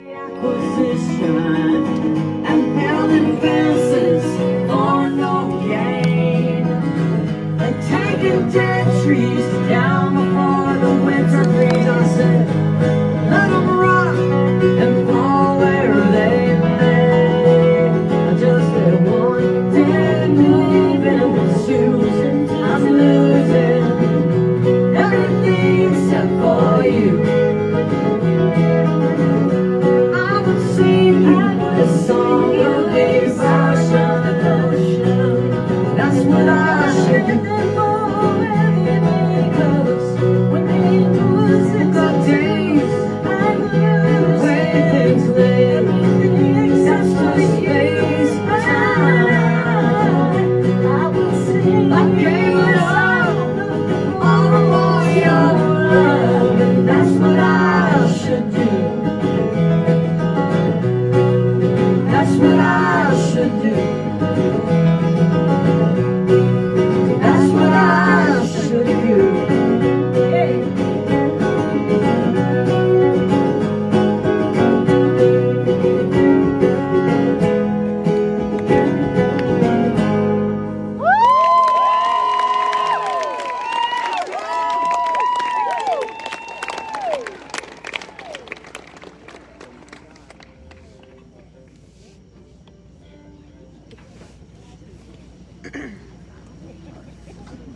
(audio capture from a facebook live stream)